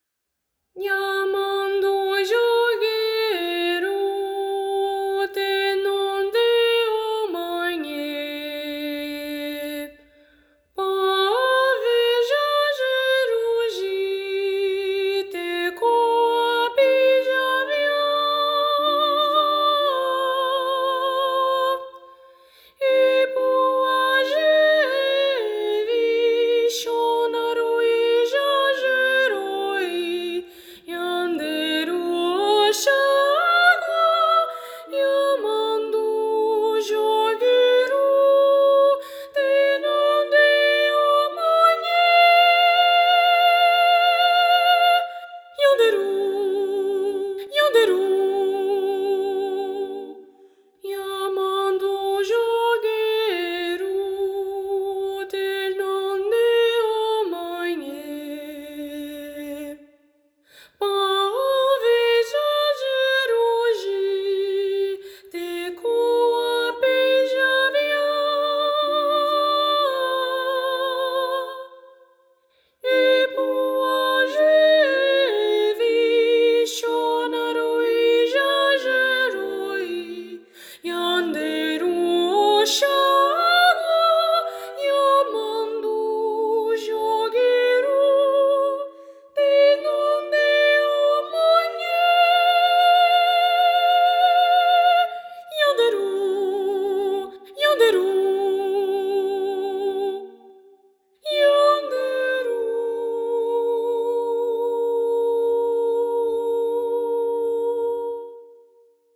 Voz Guia